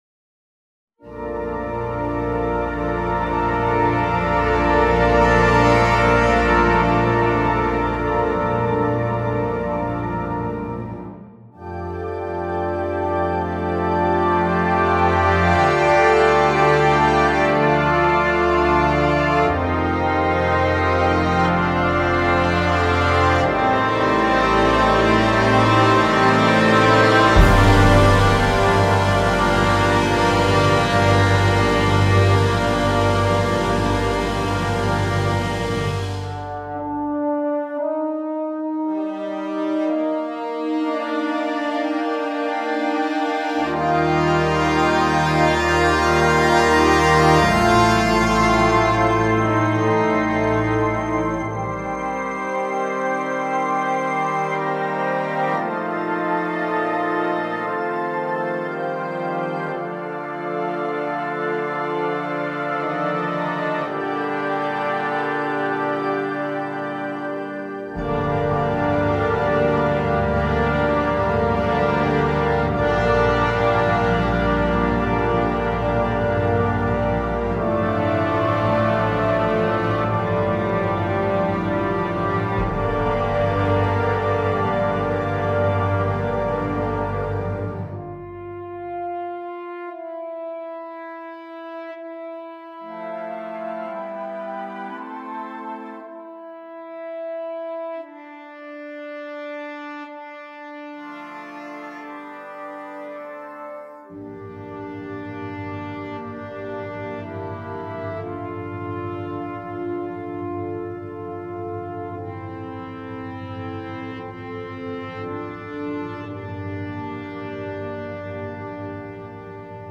2. Orchestre d'Harmonie
sans instrument solo
Composition Originale
facile